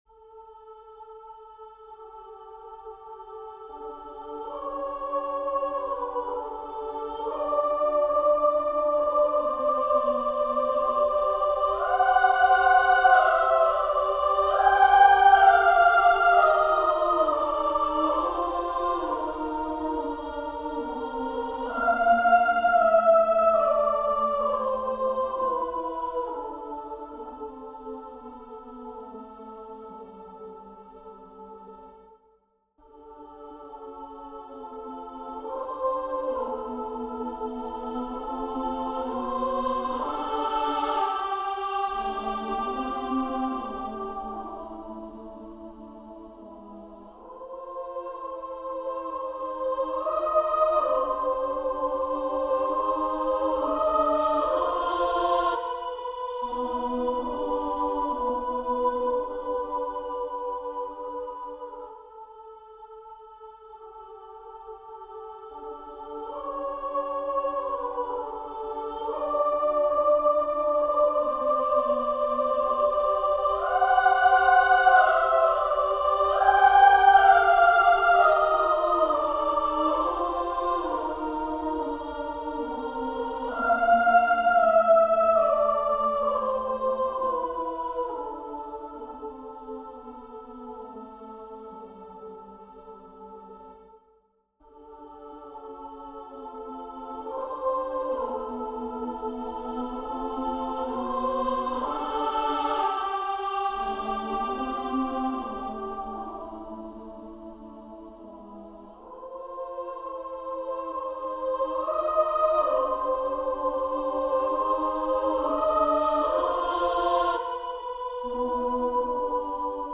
Persichetti Exercise 4 - 5 for Women's Choir
This time it is for women's choir.